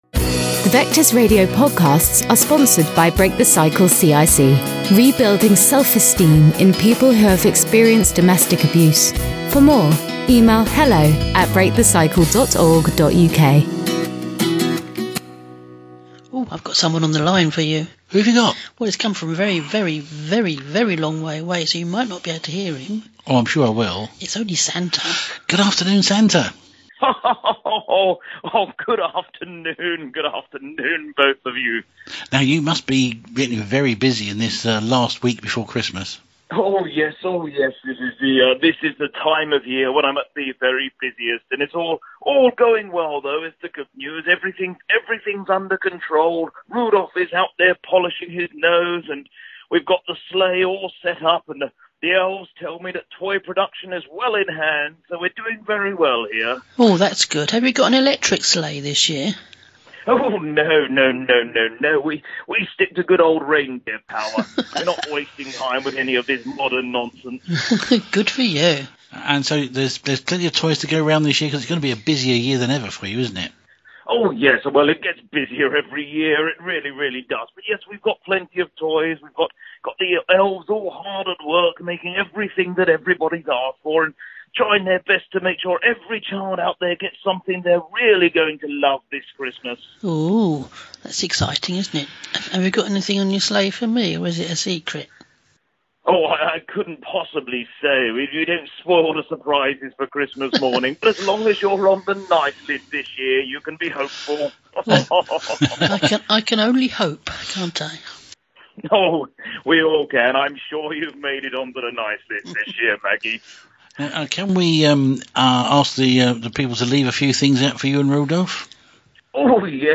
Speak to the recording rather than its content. talk to Santa on the phone.